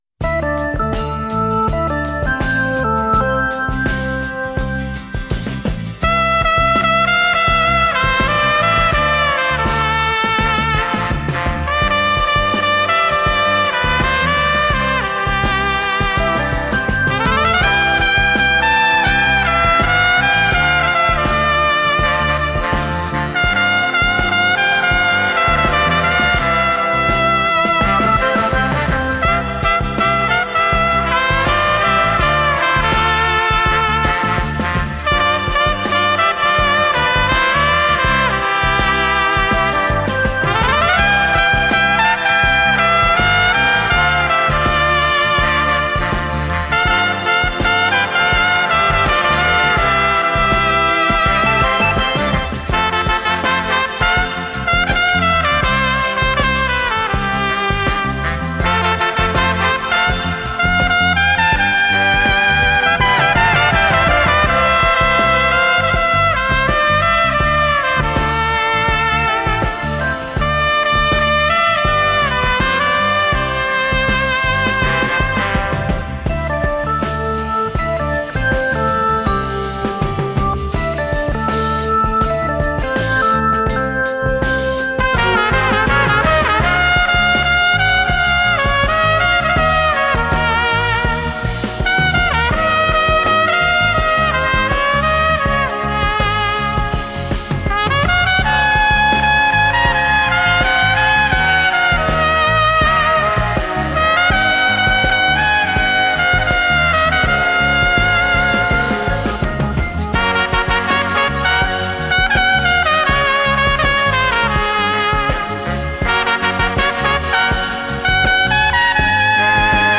Французский трубач, руководитель оркестра.